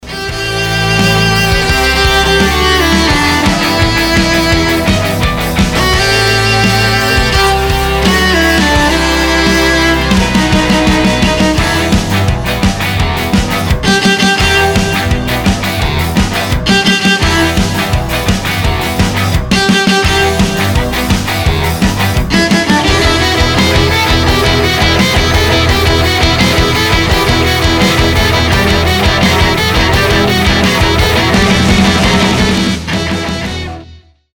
• Качество: 320, Stereo
без слов
инструментальные
виолончель
русский рок
кавер
инструментальный рок
Symphonic Rock